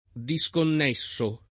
diSkonn%ttere] v.; disconnetto [diSkonn$tto o diSkonn%tto] — coniug. come connettere; part. pass. disconnesso [